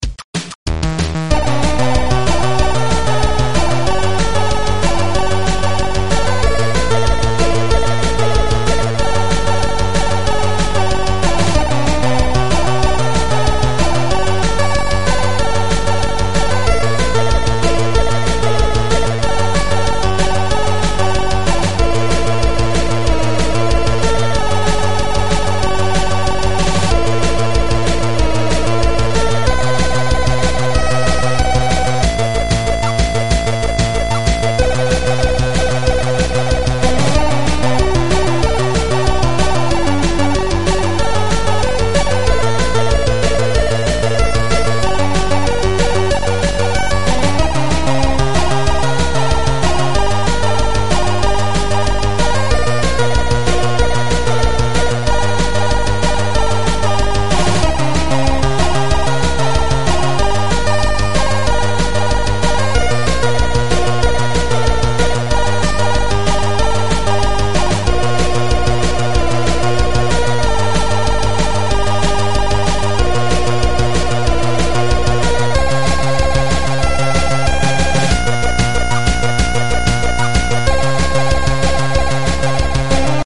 Chiptune song 2
Chiptune song introduction